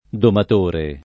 domatore [ domat 1 re ]